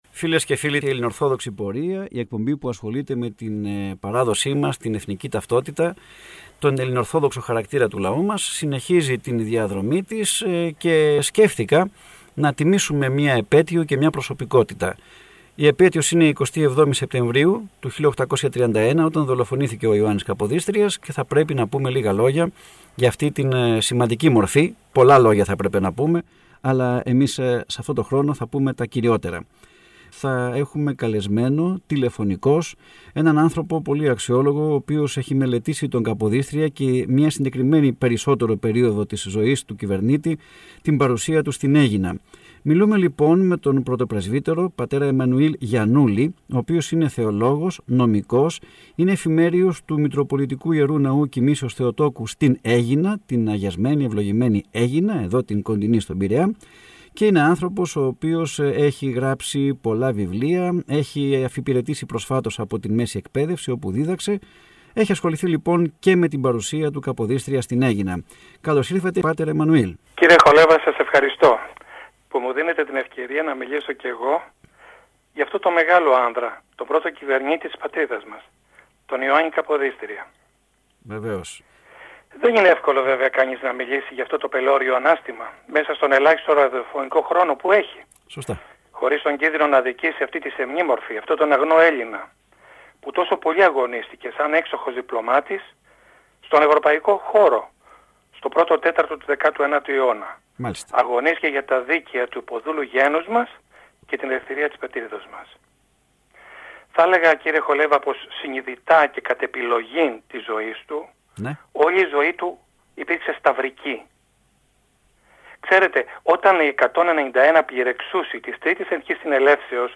Ραδιοφωνική εκπομπή Πειραϊκής Εκκλησίας "Ιωάννης Καποδίστριας"
Ραδιοφωνική εκπομπή Πειραϊκής Εκκλησίας "Ιωάννης Καποδίστριας" Ακούστε στην συνέχεια, ηχογραφημένα αποσπάσματα της ραδιοφωνικής εκπομπής «Ελληνορθόδοξη πορεία», που μεταδόθηκε από τον ραδιοσταθμό της Πειραϊκής Εκκλησίας.